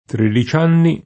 tredici [tr%di©i] num. — elis. (mai nella scrittura): tredici anni [